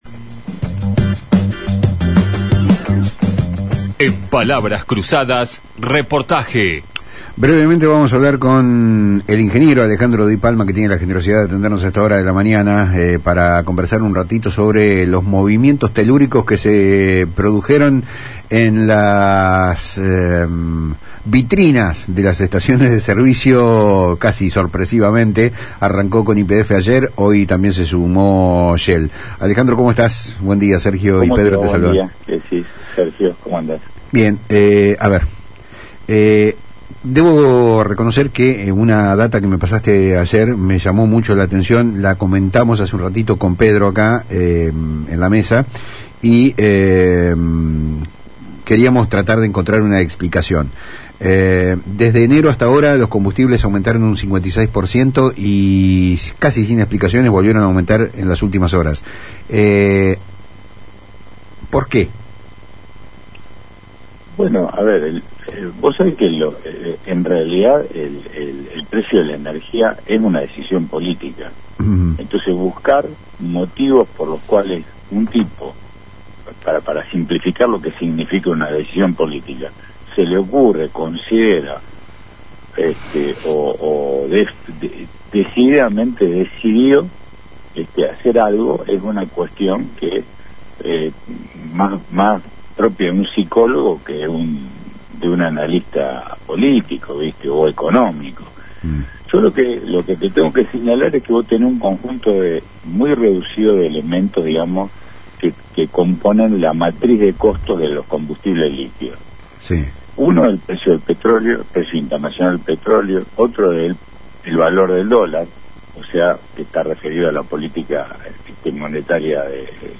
dialogó con Palabras Cruzadas de FM Litoral